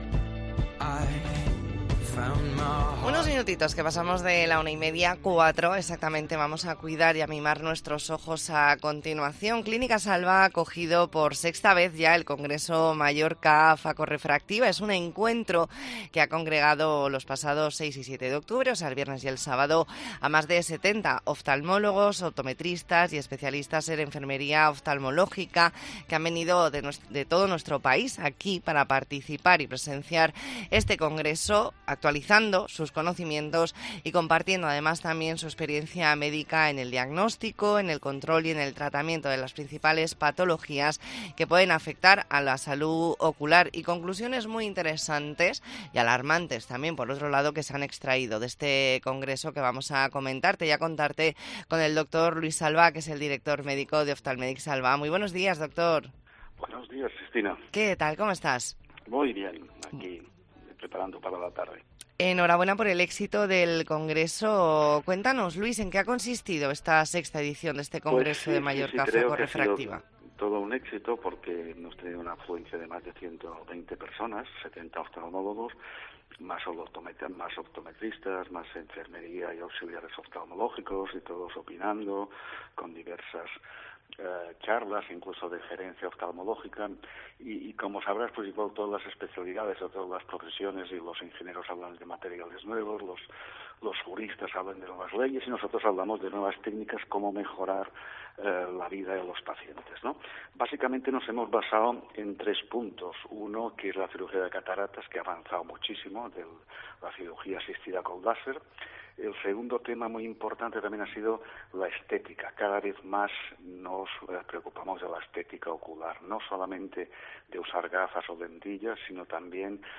Entrevista en La Mañana en COPE Más Mallorca, lunes 9 de octubre de 2023.